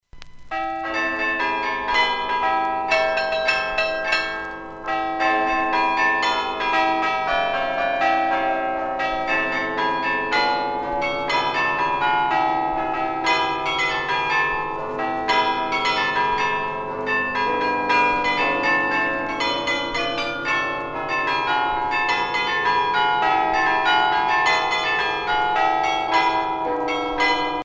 carillon du beffroi de Mons